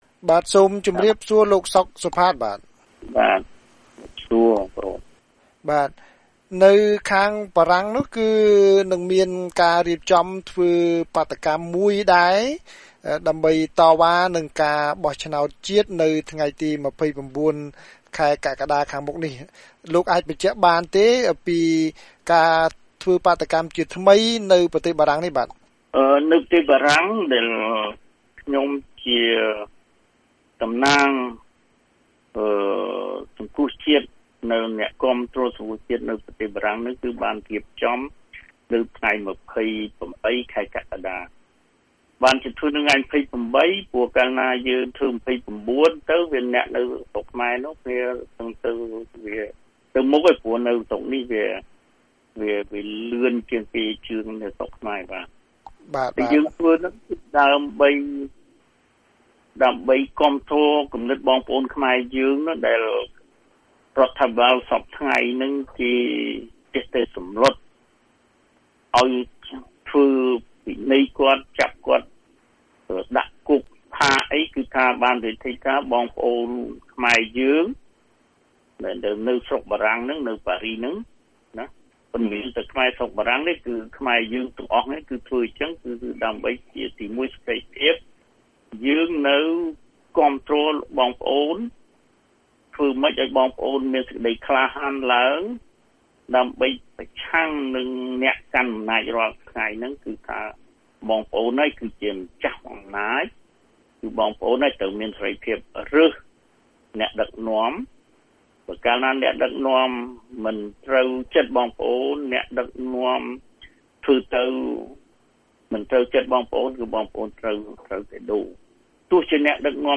បទសម្ភាសន៍ VOA៖ ខ្មែរនៅបារាំងធ្វើបាតុកម្មប្រឆាំងការបោះឆ្នោតនៅកម្ពុជា